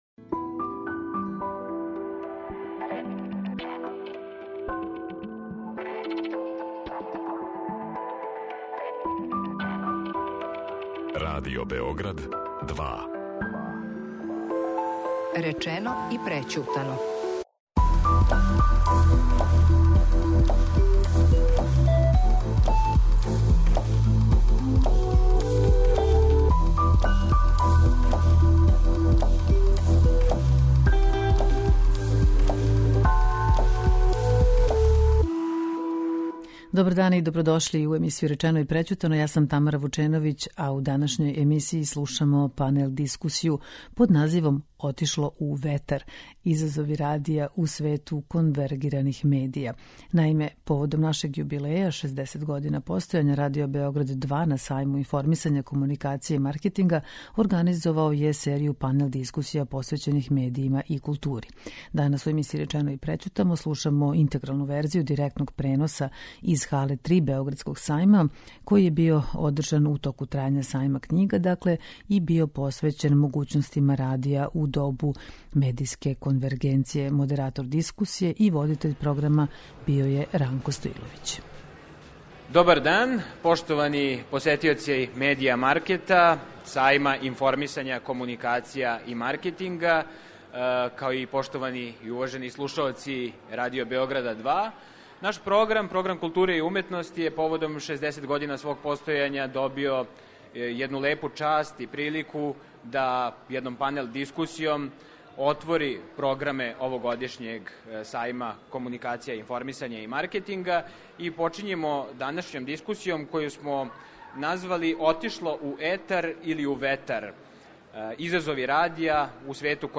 Поводом 60 година постојања, Радио Београд 2 је на прошлогодишњем Сајму информисања, комуникација и маркетинга организовао серију панел-дискусија тематски посвећених медијима и култури. У петак емитујемо снимак разговора из Хале 3 Београдског сајма који је био посвећен могућностима радија у добу медијске конвергенције.